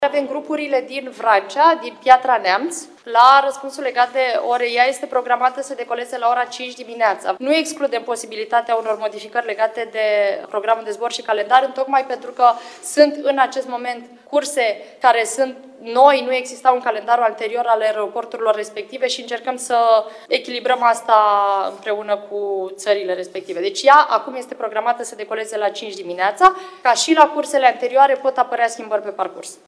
Ministra de Externe, Oana Țoiu: Zborul cu care se întorc în țară elevii din Vrancea și din Piatra-Neamț este programat la ora 05:00